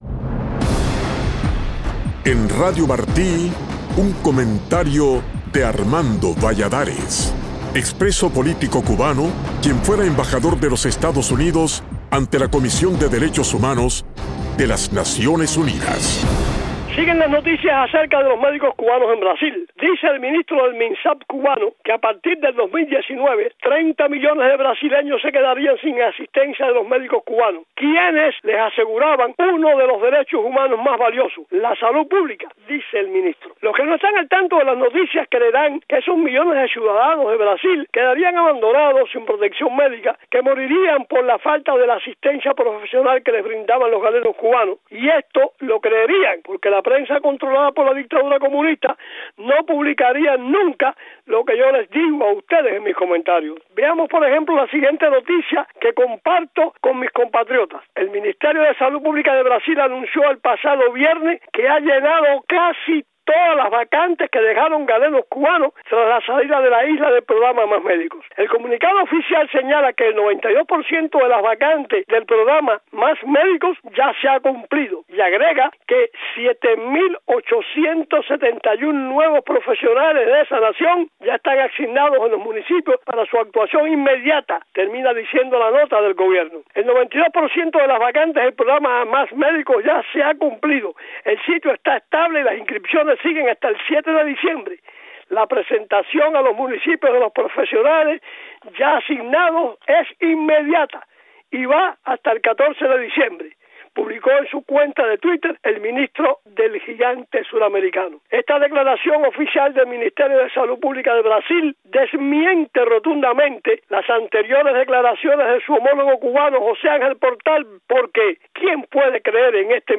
Embajador Valladares: Mi opinión